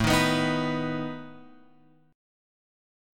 A Suspended 2nd Flat 5th